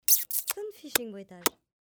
Catégorie Effets Sonores